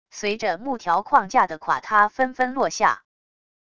随着木条框架的垮塌纷纷落下wav音频